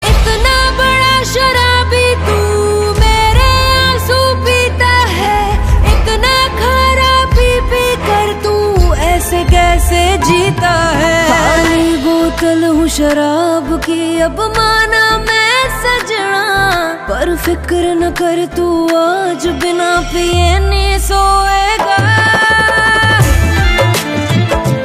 accompanied by the haunting music